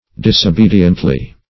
Disobediently \Dis`o*be"di*ent*ly\, adv.